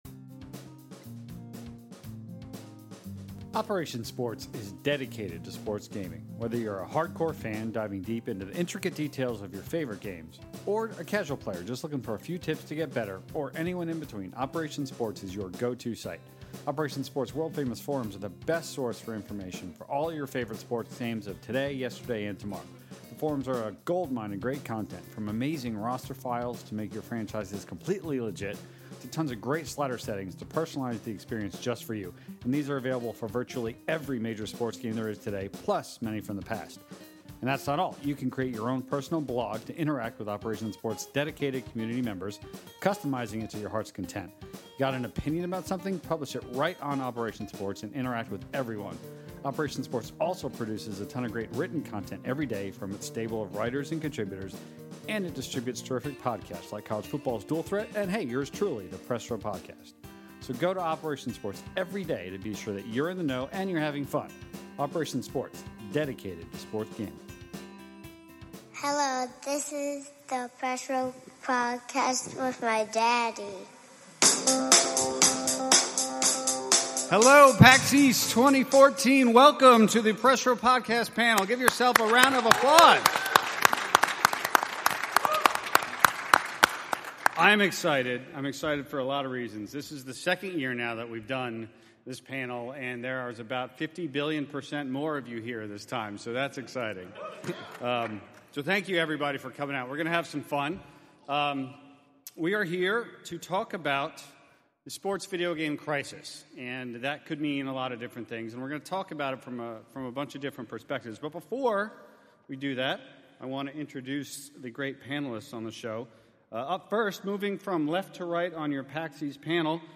These questions and many more are posed and debated in front of a live audience.